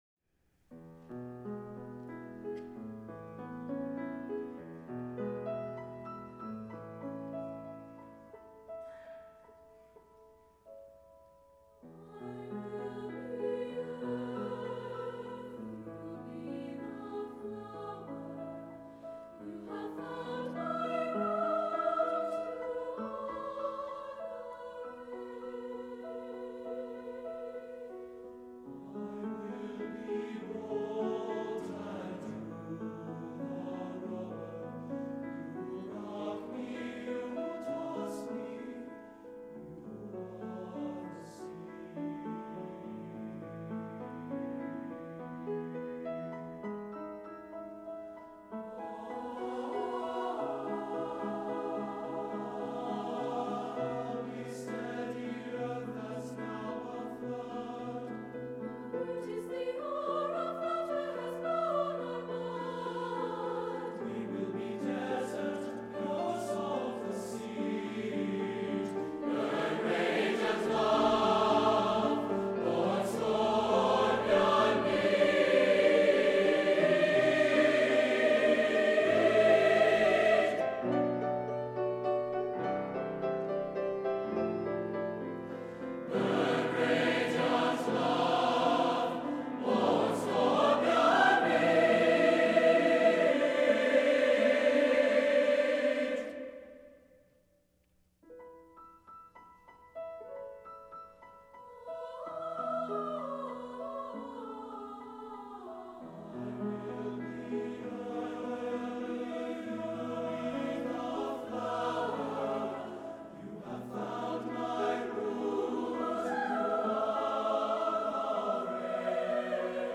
SATB and piano